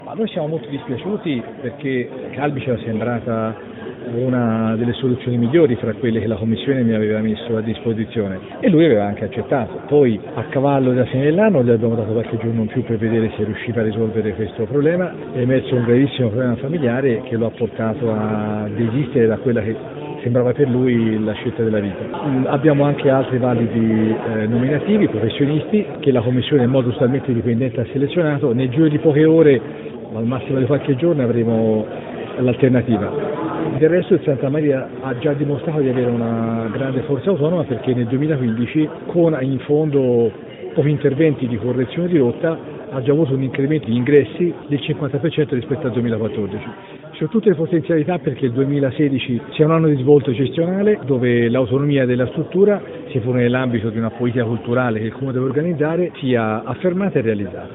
ASCOLTA LE PAROLE DEL SINDACO